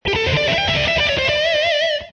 Descarga de Sonidos mp3 Gratis: guitarra 27.